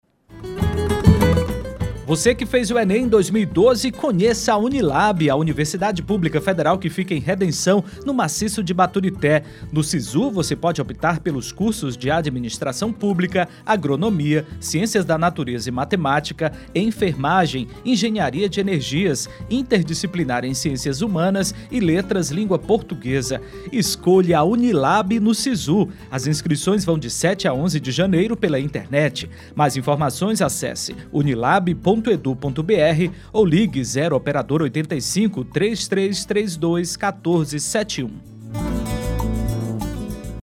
chamada de áudio para emissoras de rádio
Chamada-Unilab-SiSU-para-rádios.mp3